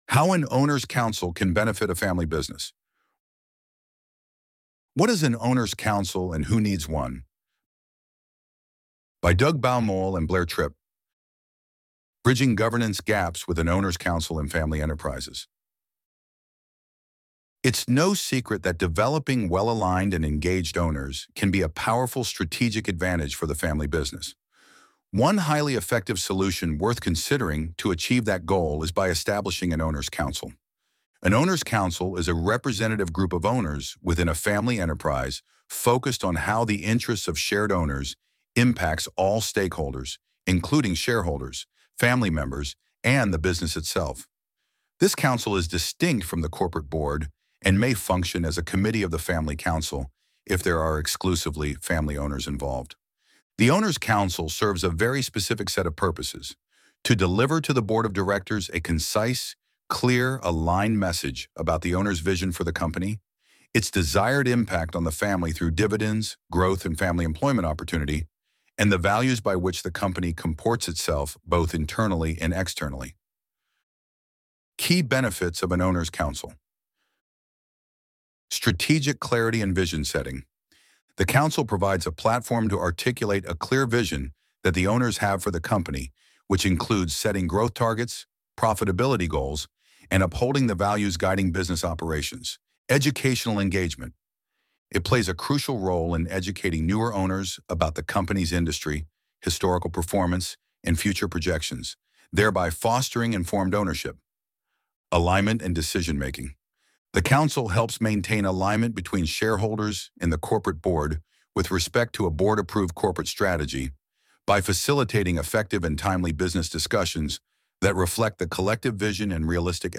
Bridging Governance Gaps with an Owners Council in Family Enterprises Loading the Elevenlabs Text to Speech AudioNative Player...